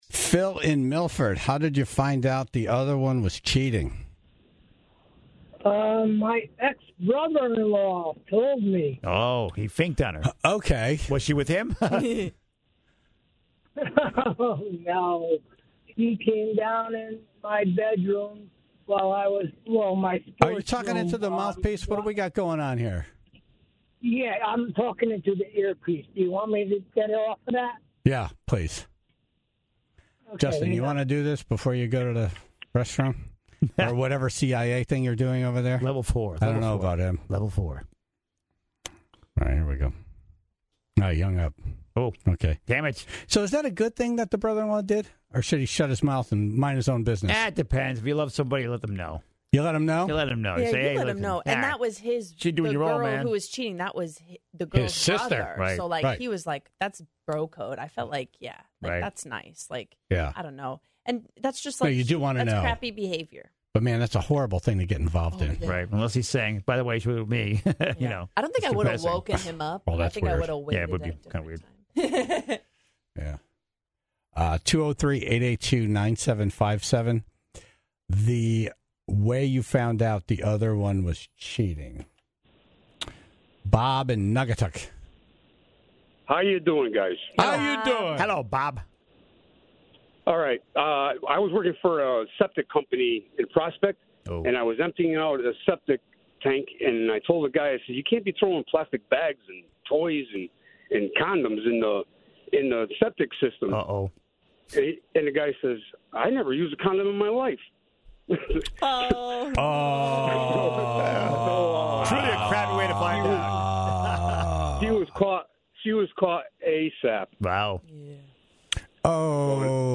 asked the Tribe to call in with the moments they found out they were being cheated on...and then the calls took some very interesting twists and turns!